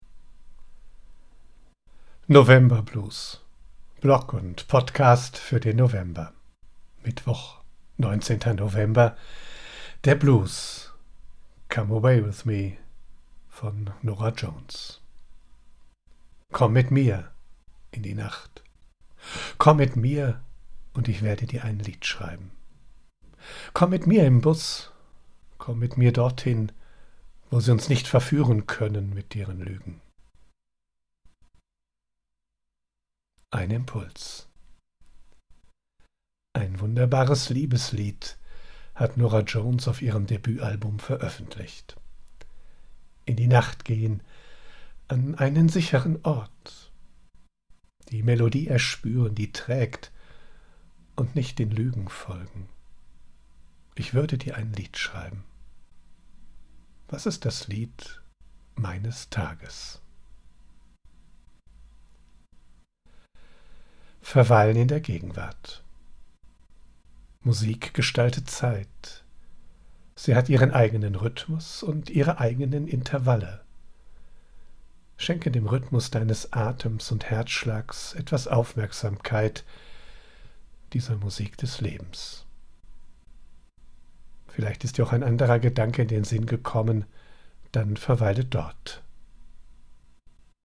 00:00:00 Der Blues: Come away with me (Nora Jones)